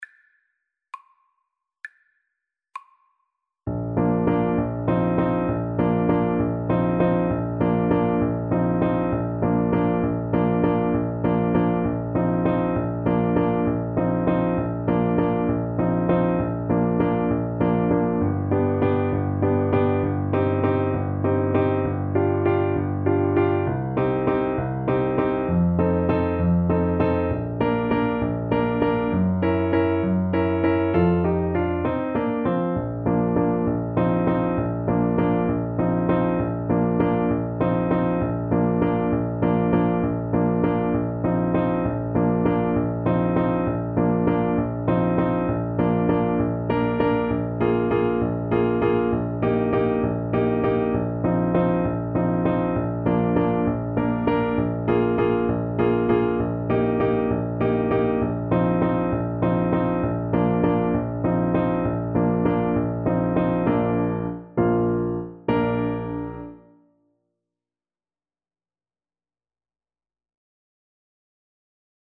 6/8 (View more 6/8 Music)
. = 66 No. 3 Grazioso